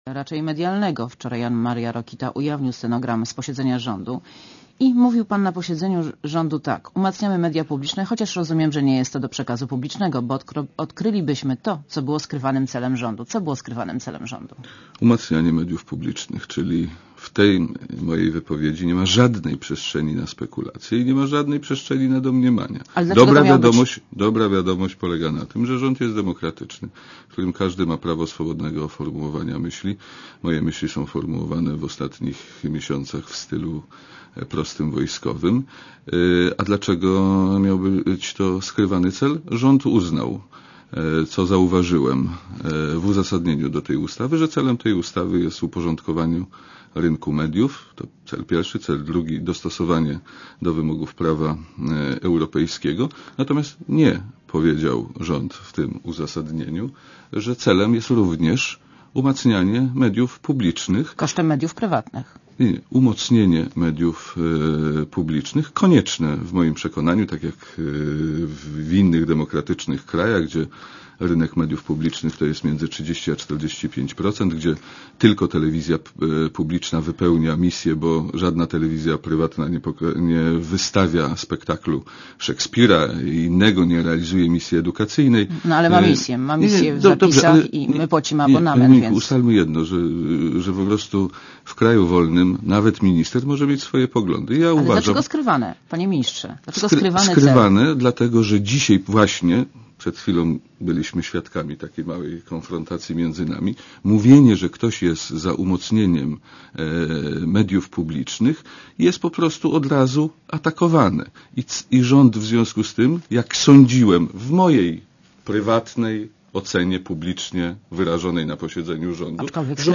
Monika Olejnik rozmawia z Jerzym Szmajdzińskim -ministrem obrony narodowej